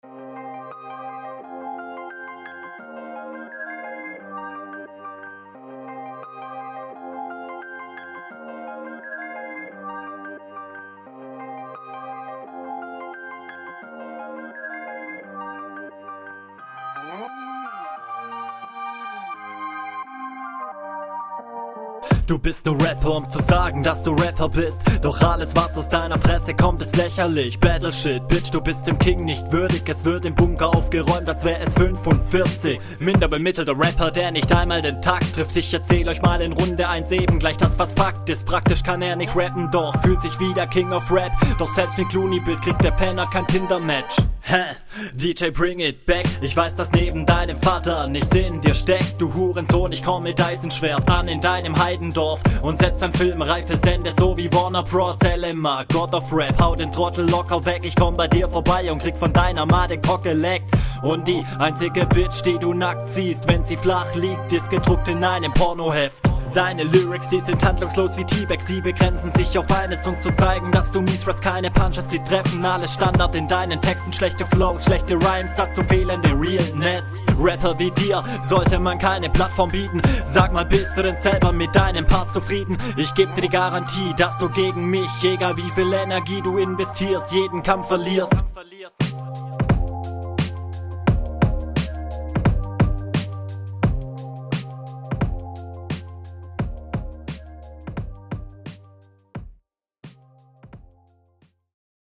Mische schonmal ganz gut für die Entry, es ist alles verständlich und die Stimme passt …
Runde ist cool, bist routiniert! bissel monoton an sich aber reime sind ok ! mal …
Hello, Beat ist eigentlich recht fett, nicht mein Ding aber hat was.